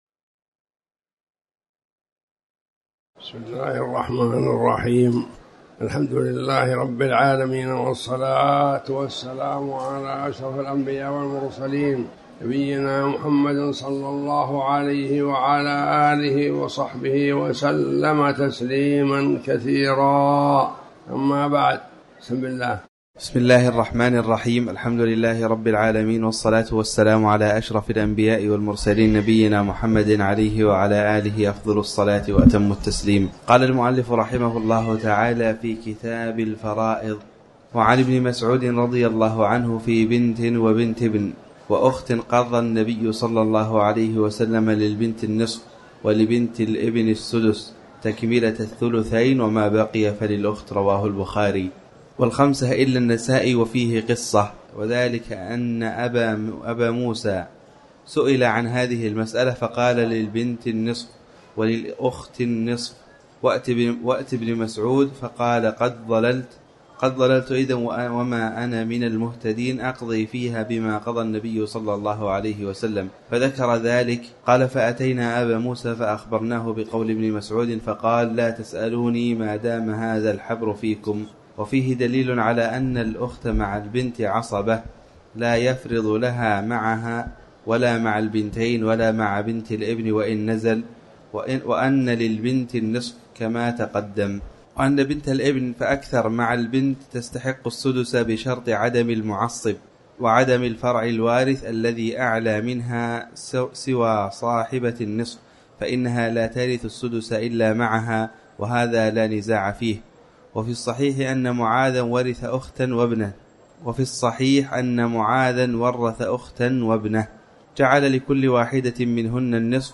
تاريخ النشر ١ شعبان ١٤٤٠ هـ المكان: المسجد الحرام الشيخ